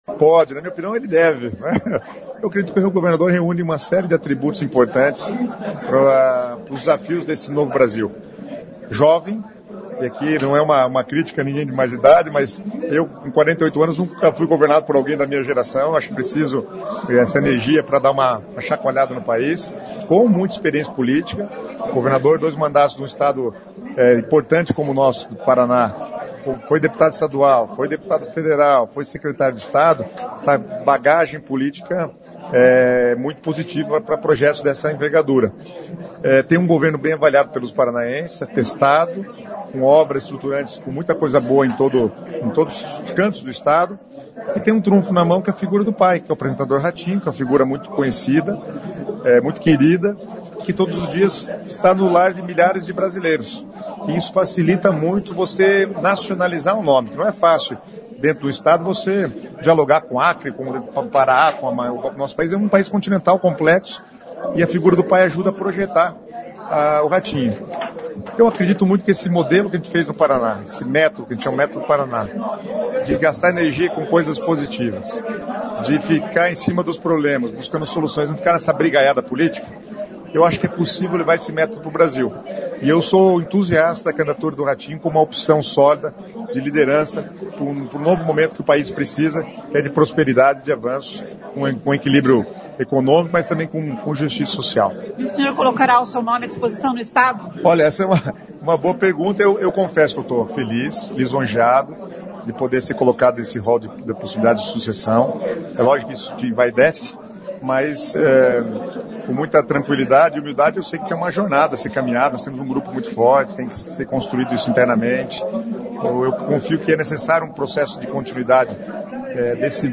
Em evento da Amusep nesta quinta-feira (24), o secretário das Cidades Guto Silva falou aos repórteres sobre política. Questionado se o governador Ratinho Júnior pode mesmo concorrer à presidência da República em 2026, Guto Silva, que além de colega de trabalho é amigo do governador, diz que não só pode como deve porque reúne qualidades que o país precisa.